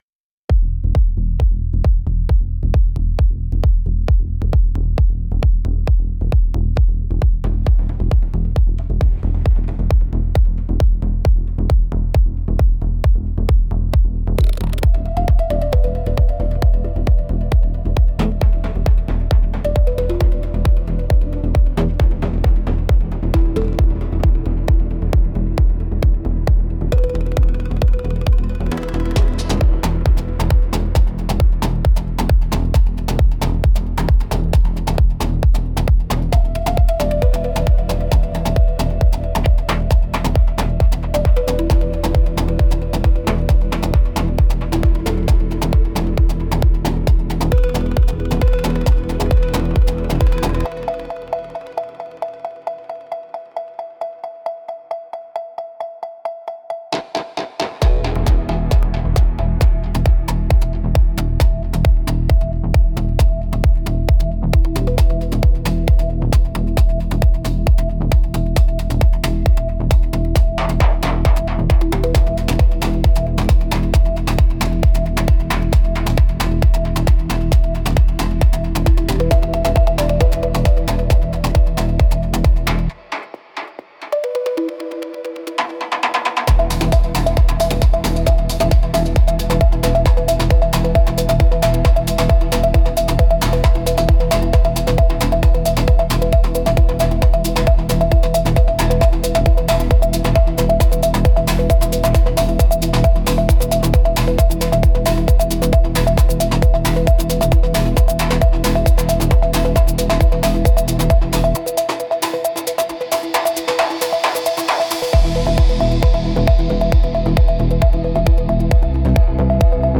Instrumentals - The Hum Beneath the Soil